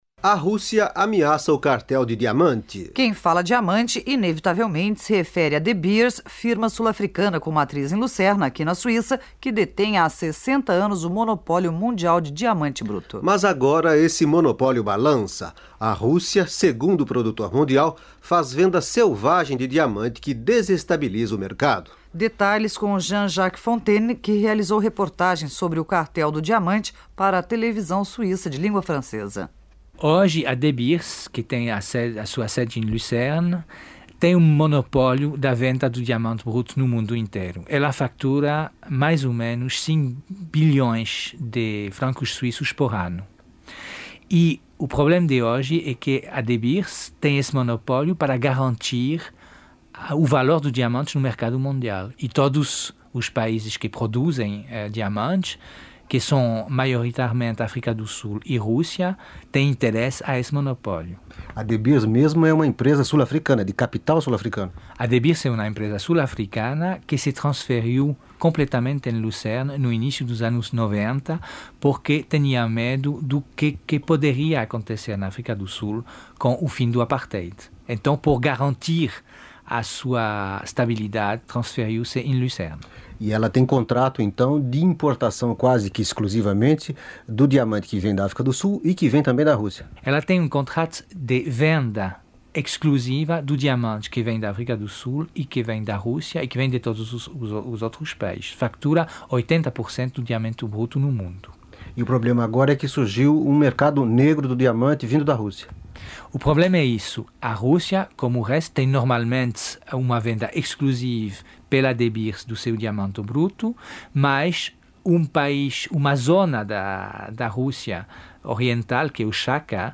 Entrevista da Rádio Suíça Internacional com o jornalista